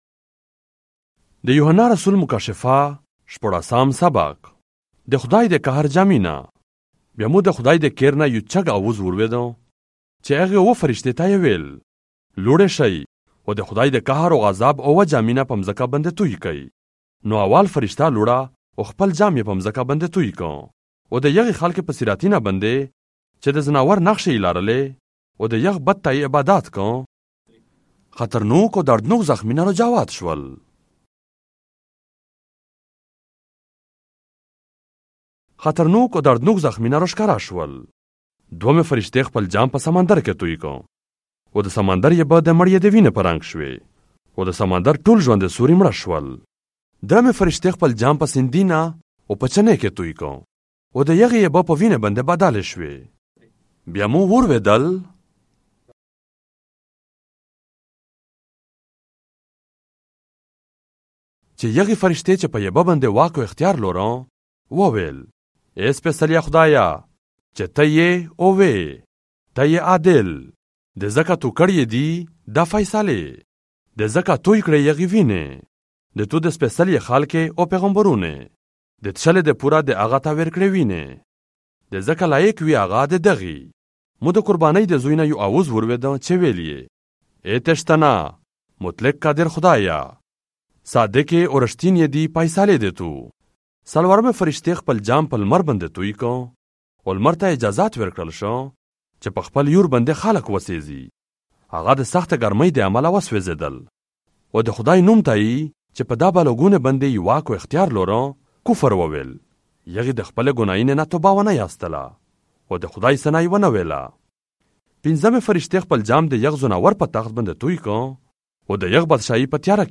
مکاشفه ۱۶ در کتاب مقدس به زبان پشتو، مرکزی (صوتی) ۲۰۲۵